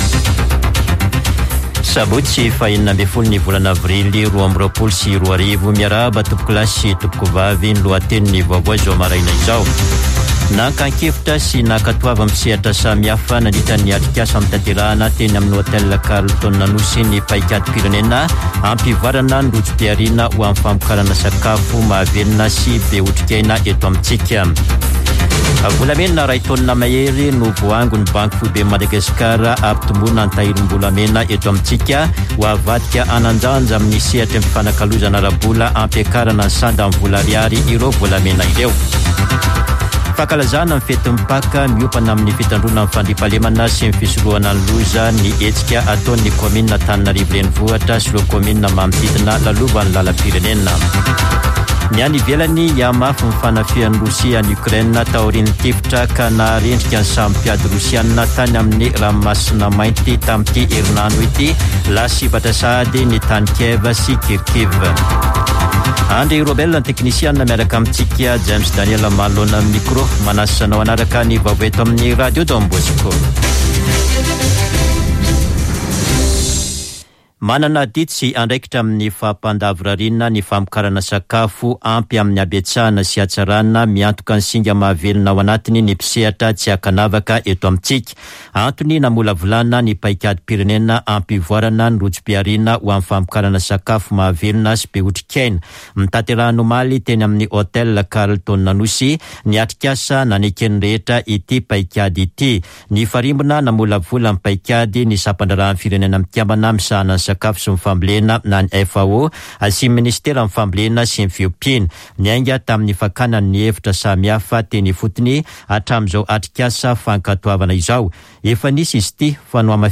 [Vaovao maraina] Sabotsy 16 aprily 2022